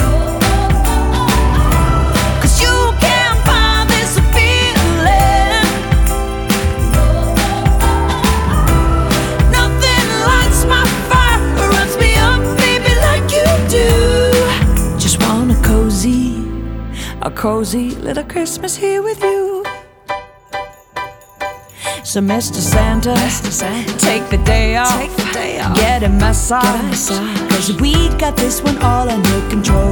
• Holiday
Christmas song